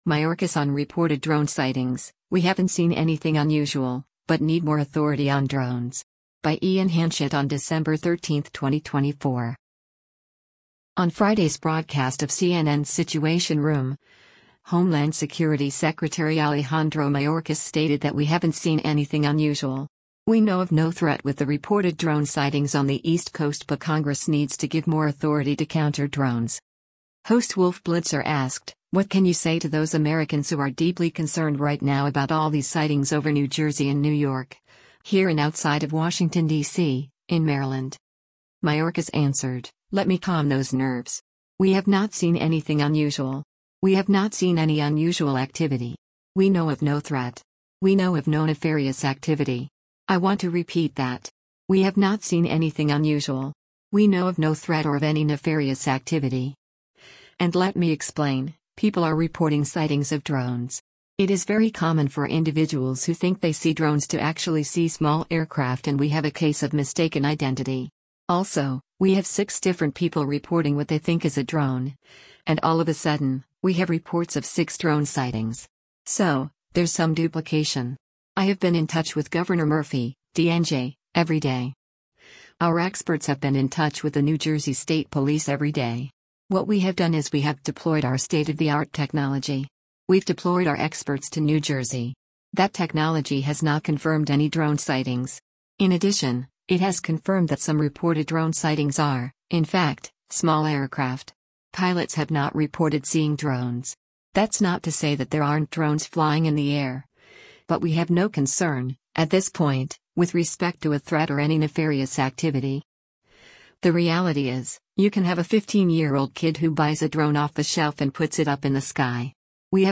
On Friday’s broadcast of CNN’s “Situation Room,” Homeland Security Secretary Alejandro Mayorkas stated that “We haven’t seen anything unusual. We know of no threat” with the reported drone sightings on the East Coast but Congress needs to give more authority to counter drones.